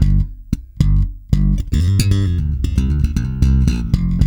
-JP THUMB G.wav